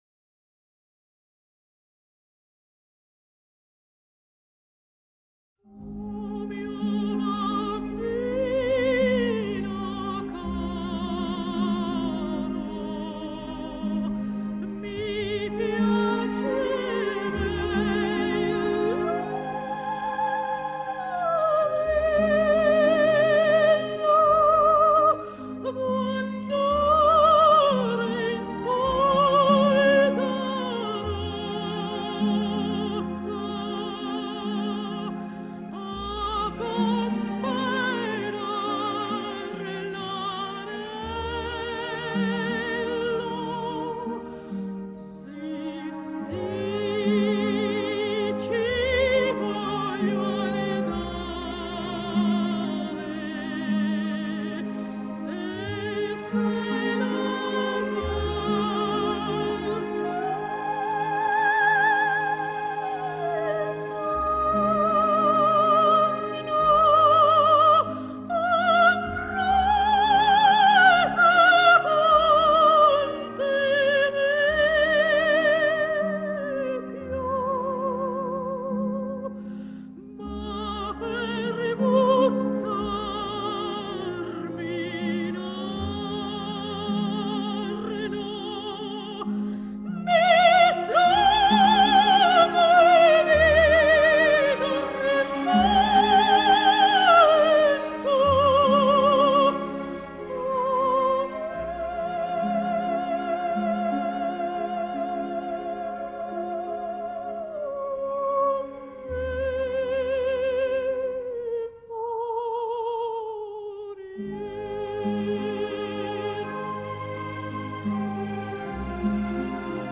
“O mio babbino caro”, Aria per soprano.
Presentata da Montserrat Caballé.